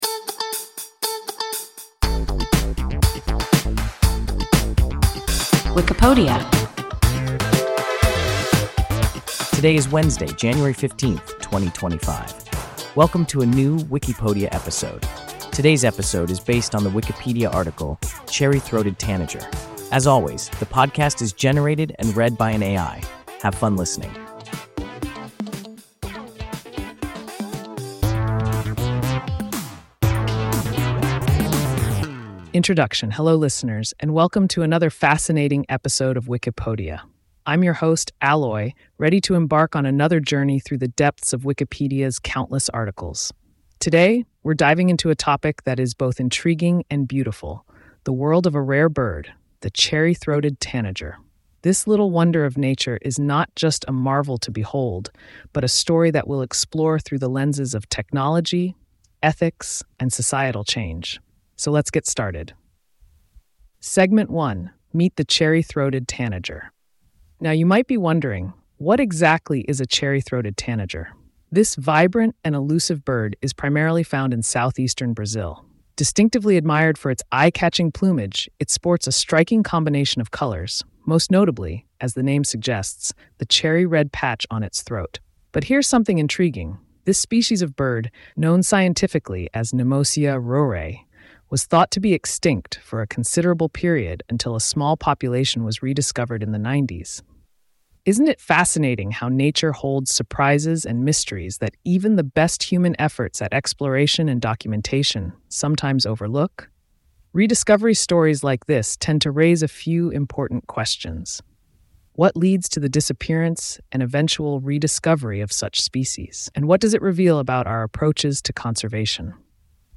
Cherry-throated tanager – WIKIPODIA – ein KI Podcast